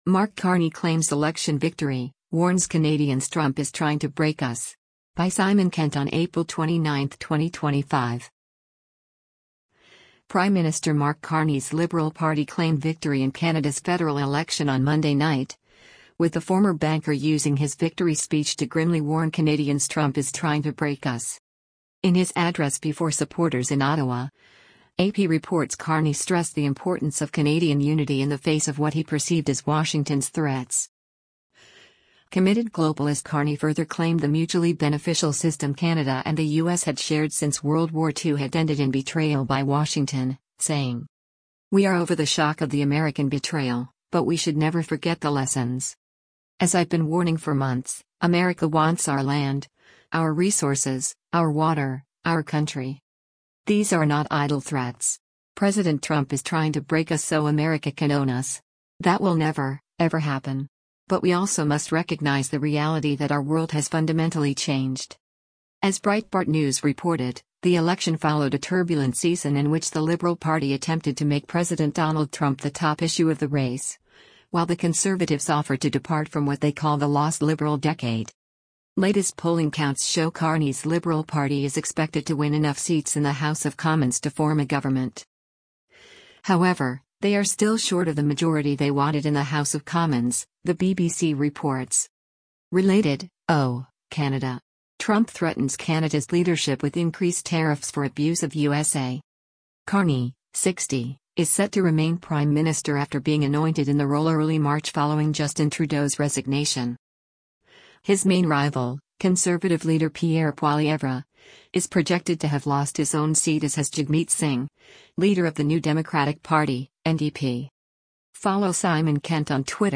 In his address before supporters in Ottawa, AP reports Carney stressed the importance of Canadian unity in the face of what he perceived as Washington’s threats.